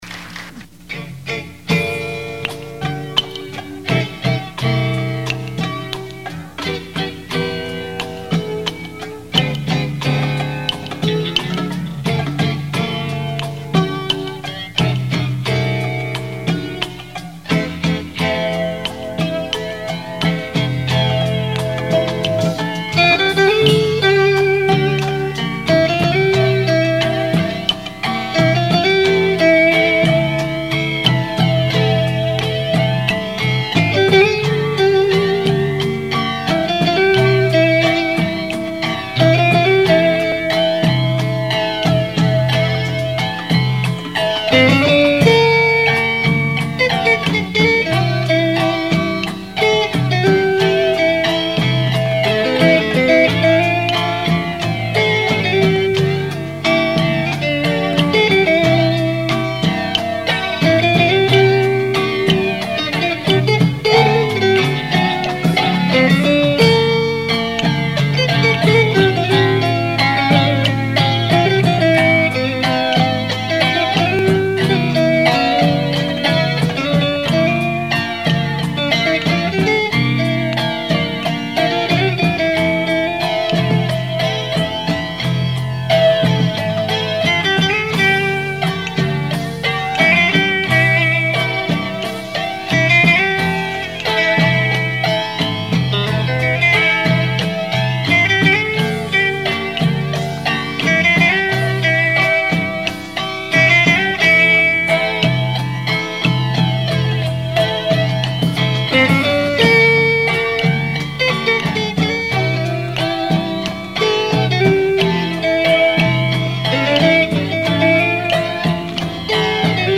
980   06:02:00   Faixa:     Rock Nacional